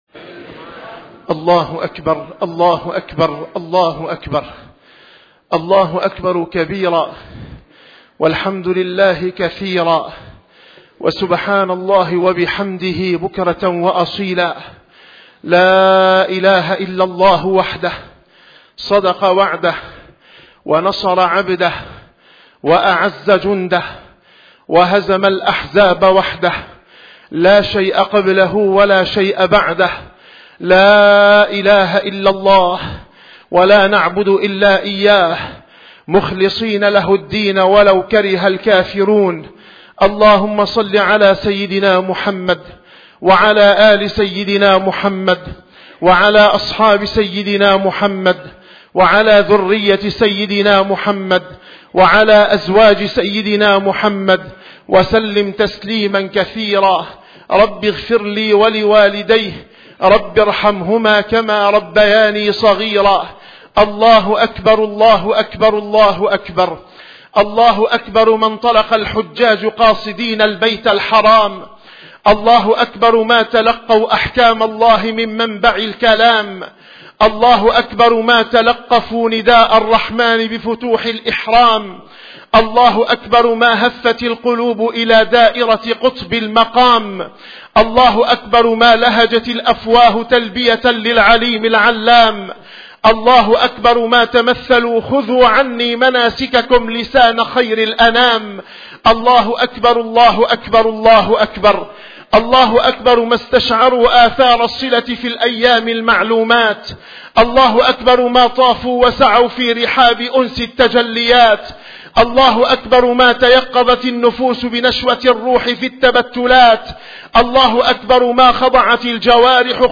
- الخطب - خطبة عيد الأضحى: فلسفة قصة الذبيح